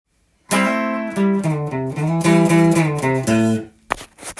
フレーズ②
誰もが使いそうな王道フレーズなので